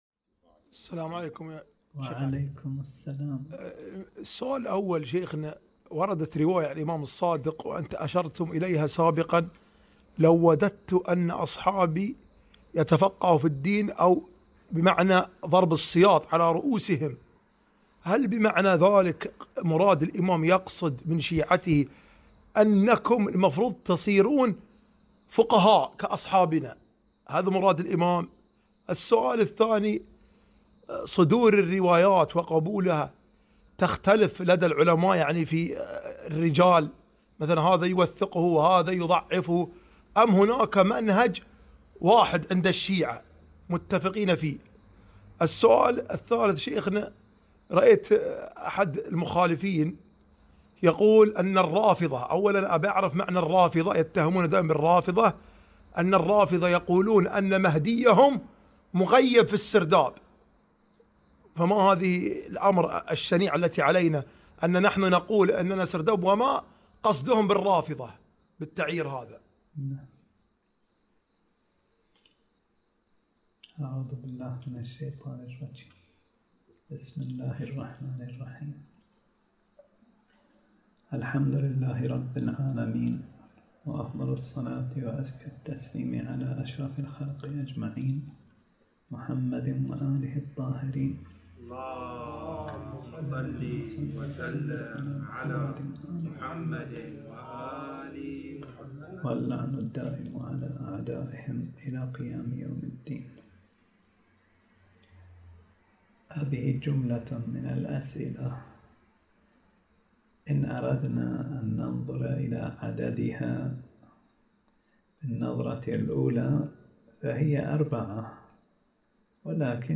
درس ليلة الأحد 7 شهر شعبان 1442 هـ